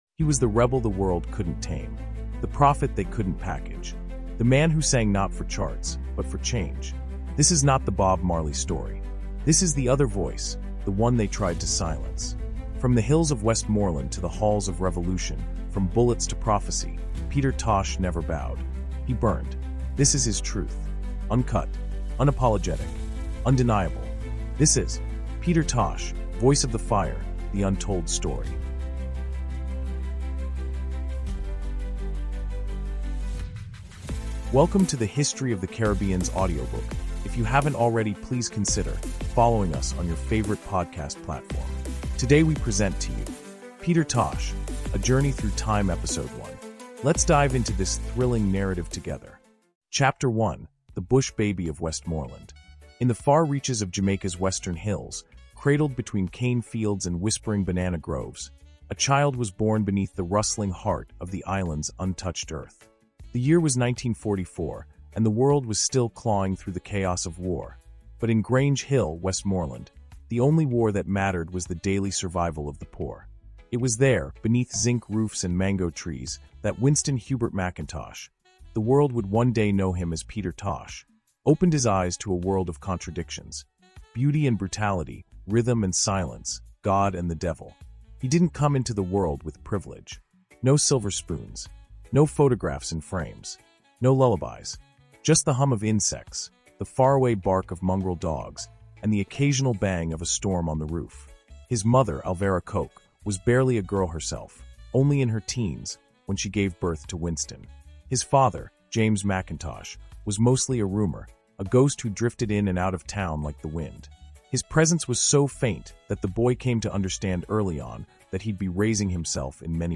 Peter Tosh: Voice of the Fire – The Untold Story is a gripping 16-chapter cinematic audiobook that resurrects the life, music, and martyrdom of one of reggae’s fiercest revolutionaries. From his rise with The Wailers to his assassination in 1987, this raw and emotionally charged narrative exposes the industry sabotage, political conspiracies, and spiritual battles behind the man who refused to bow. Featuring detailed storytelling written for AI narration, this audiobook is more than a biography—it’s a rebellion in words.